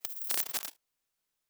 pgs/Assets/Audio/Sci-Fi Sounds/Electric/Glitch 3_06.wav at master
Glitch 3_06.wav